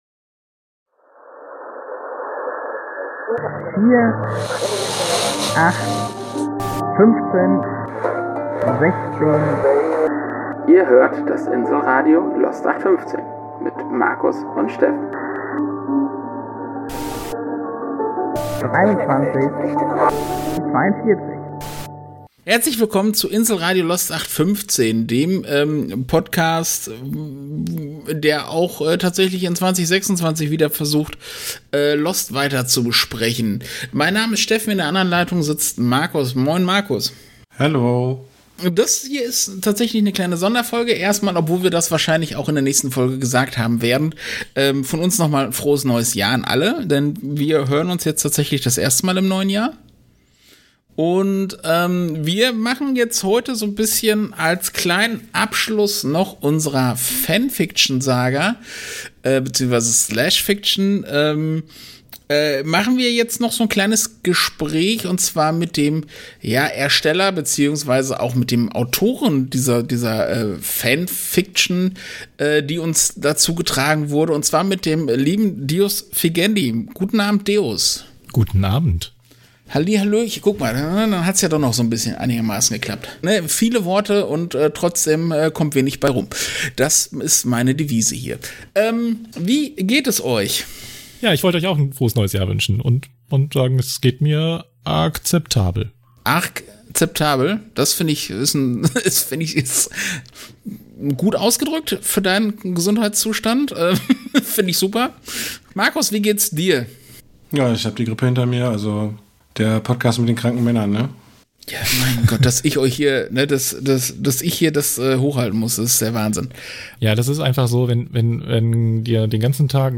FanFiction_11_-_Das_Gespraech_danach.mp3